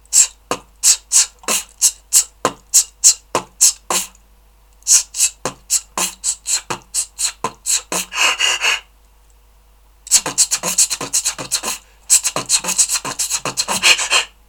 аудио с битбоксом
t b t t pf t t b t t b t pf
t t b t pf t t b t t b t pf bri